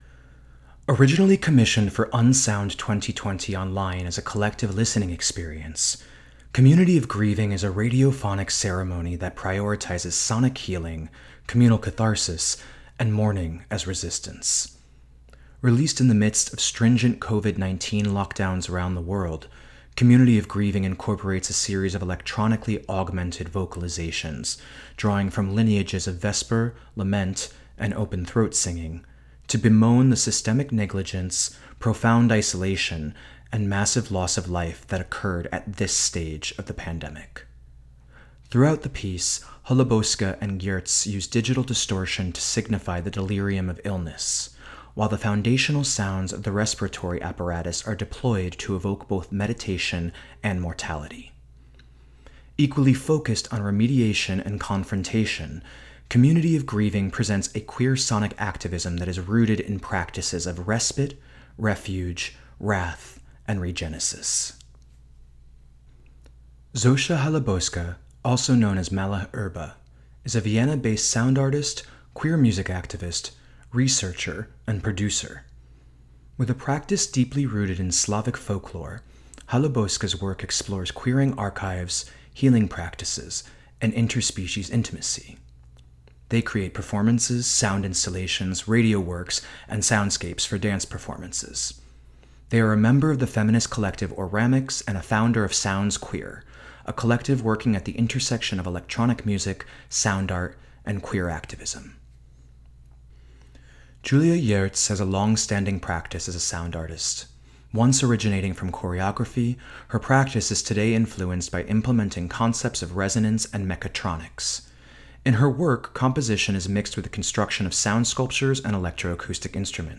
a radiophonic ceremony
digital distortion